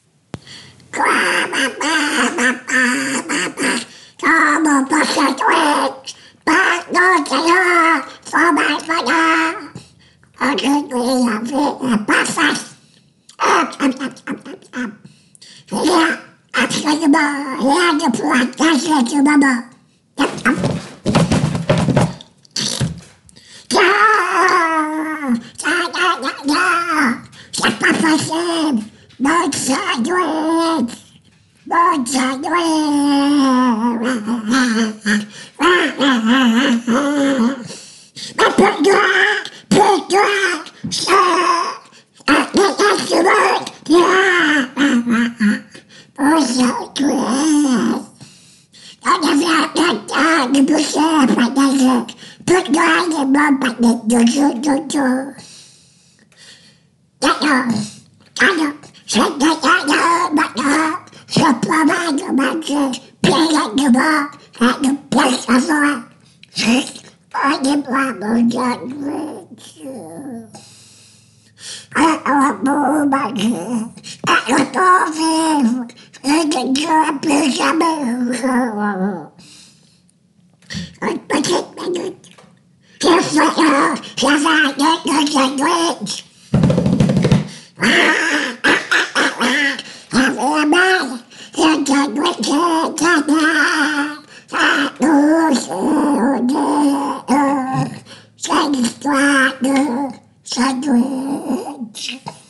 Doublage de voix : le Canard - VoxPopuli - imitation
Doublage de voix : le Canard